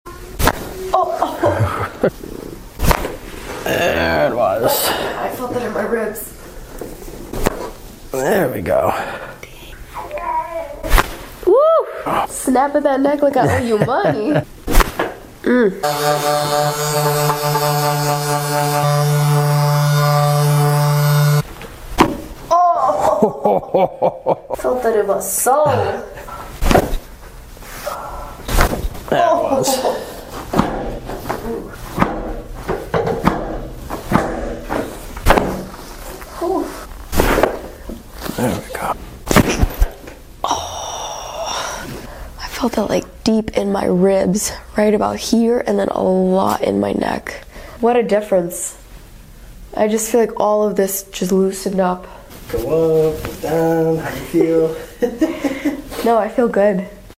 Inventor of LOUDEST BACK CRACKS sound effects free download